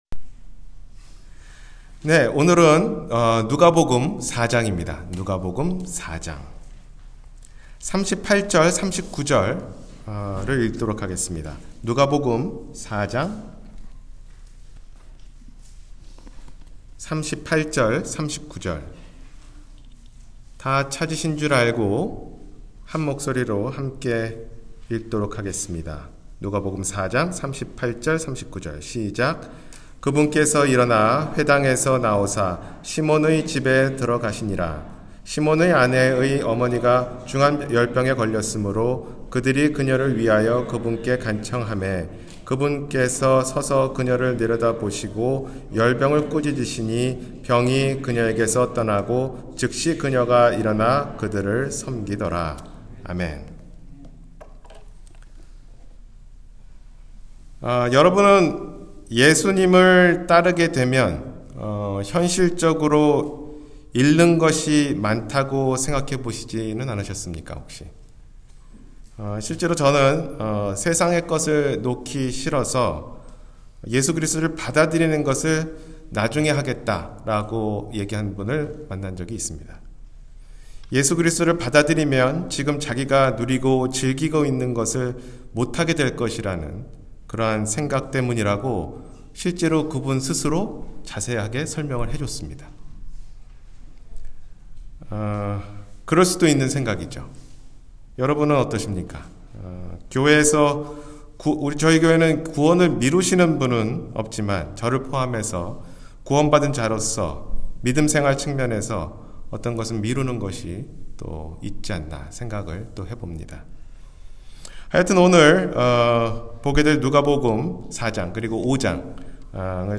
잃는 것과 얻는 것 – 주일설교